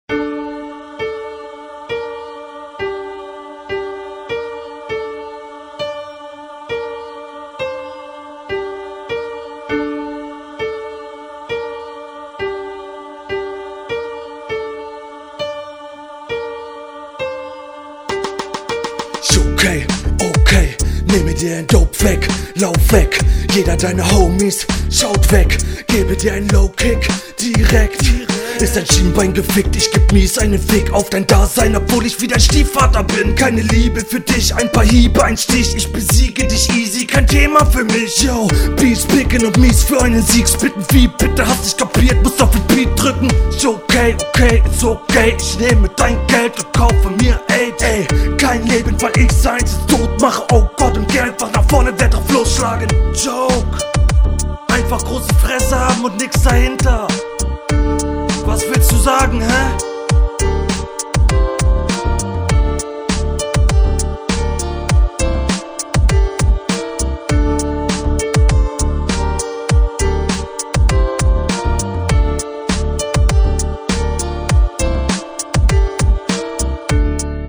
Der Beat klingt ein bisschen billig. Die Mische klingt soweit ganz gut.
Finde die Qualität ist mager und die Punches sind Mittelmässig, Brauchst besseres Mix/Master!⠀⠀⠀⠀⠀⠀⠀
Beat erinnert mich bisschen so an den Berliner Untergrund von damals oder die Sekte.